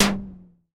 空气拍打" 踢打塑料管
描述：踢塑料管ZOOM H1
标签： 塑料 打击乐
声道立体声